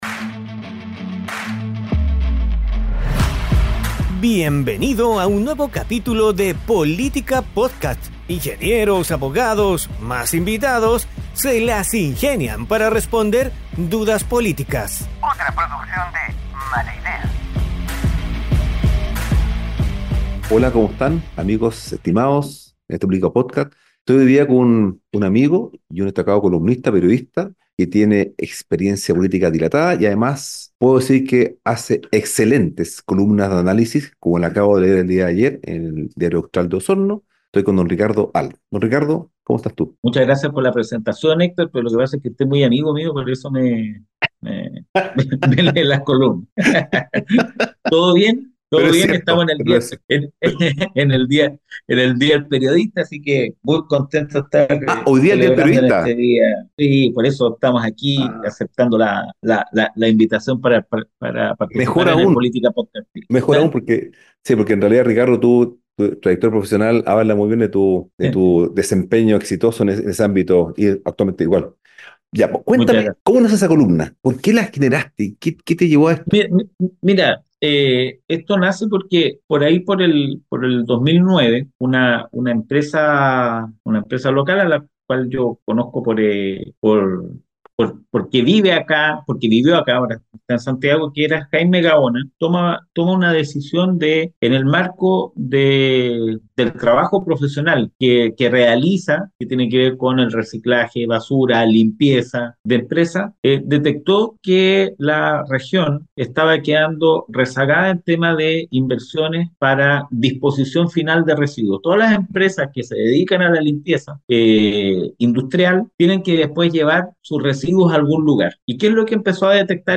La conversación abordó la problemática de la disposición de residuos tanto industriales como domiciliarios y la insuficiencia de infraestructuras adecuadas.